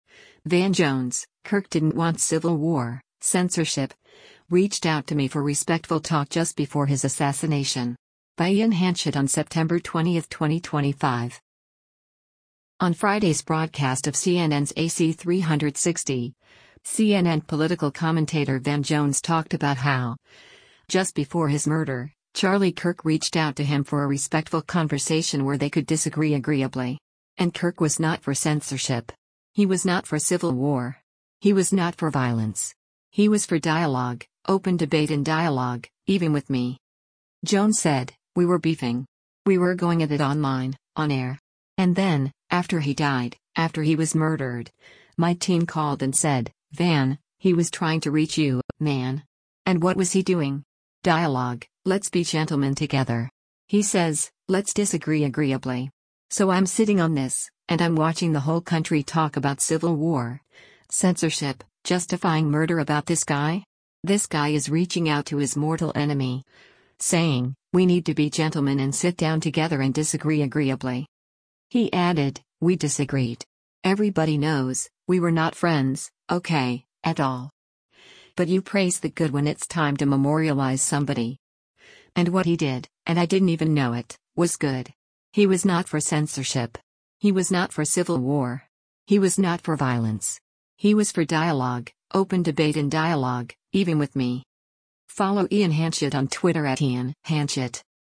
On Friday’s broadcast of CNN’s “AC360,” CNN Political Commentator Van Jones talked about how, just before his murder, Charlie Kirk reached out to him for a “respectful conversation” where they could disagree “agreeably.”